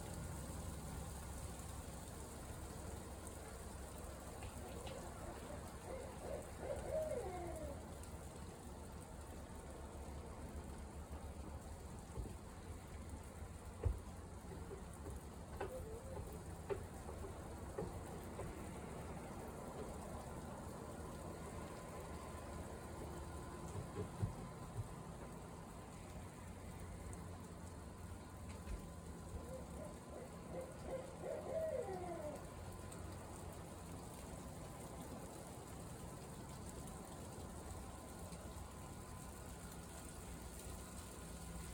Shorter-Owl-Audio.m4a